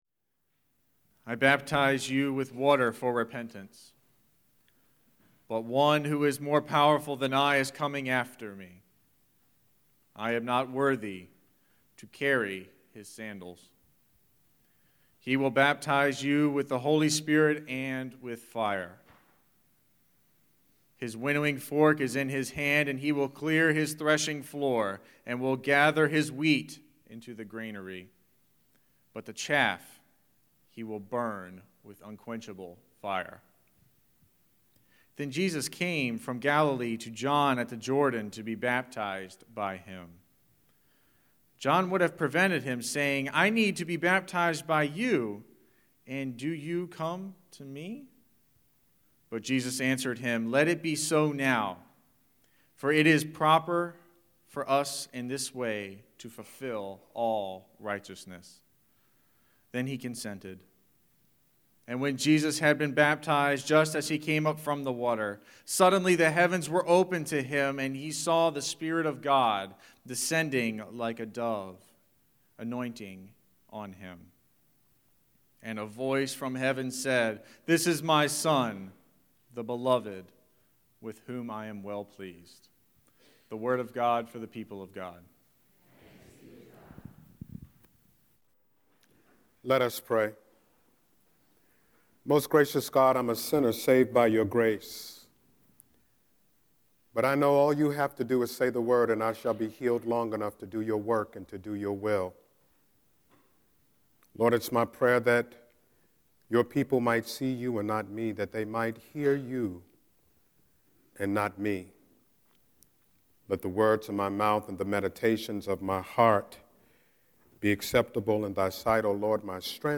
01-10-Scripture-and-Sermon.mp3